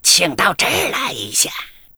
文件 文件历史 文件用途 全域文件用途 Gbn_amb_02.ogg （Ogg Vorbis声音文件，长度2.0秒，115 kbps，文件大小：28 KB） 源地址:游戏语音 文件历史 点击某个日期/时间查看对应时刻的文件。